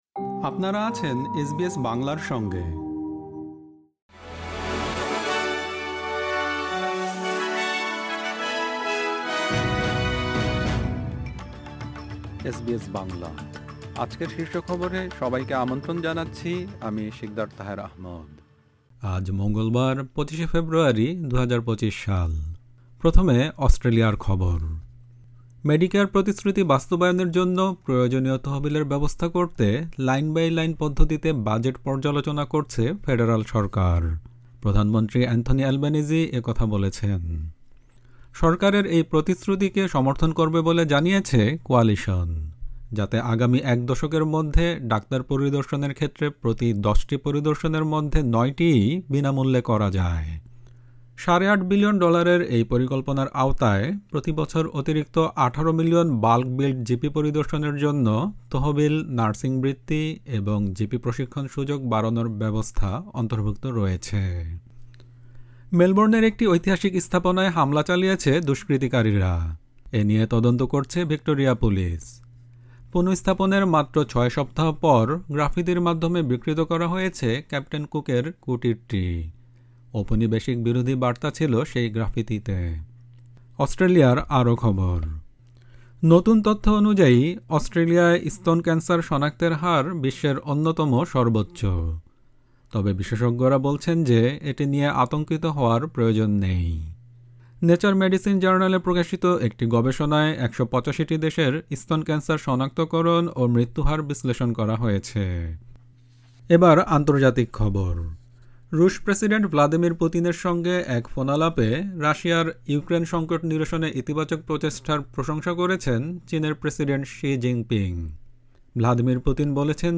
এসবিএস বাংলা শীর্ষ খবর: ২৫ ফেব্রুয়ারি, ২০২৫